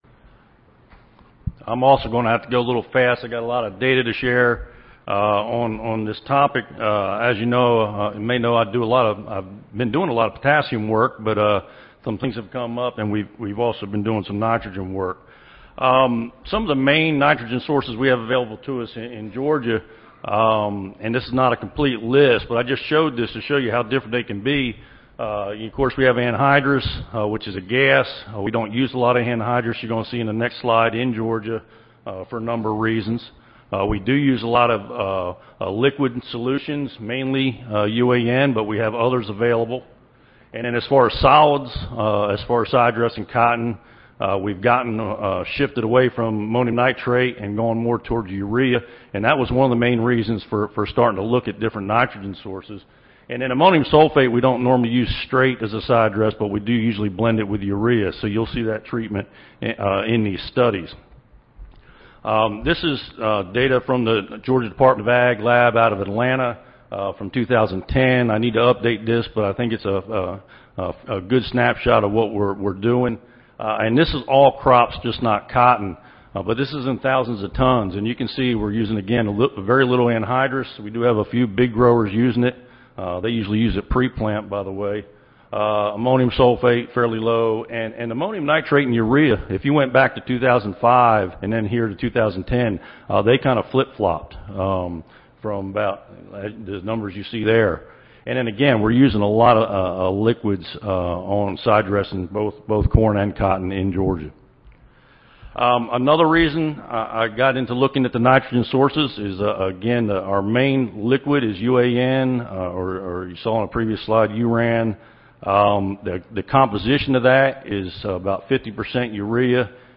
University of Georgia Audio File Recorded Presentation Replicated small plot field trials were conducted in 2013 and 2104 to evaluate both solid and liquid sidedress nitrogen fertilizers for cotton.